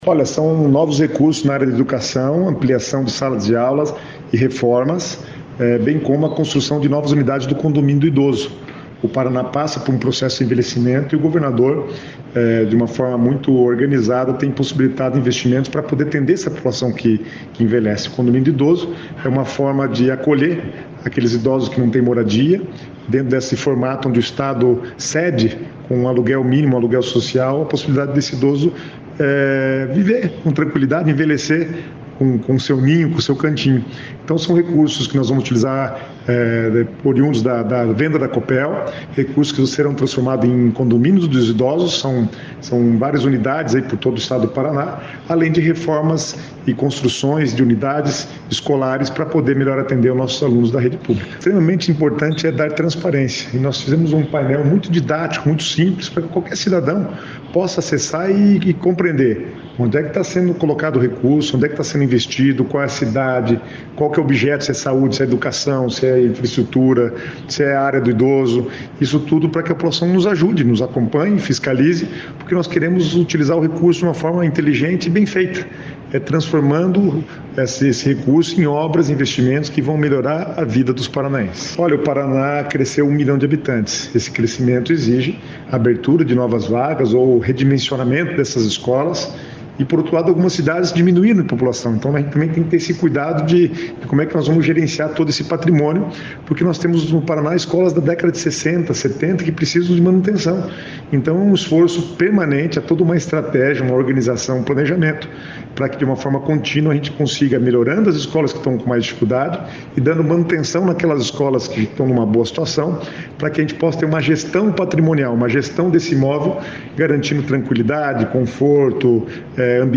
Sonora do secretário do Planejamento, Guto Silva, sobre o novo painel da Copel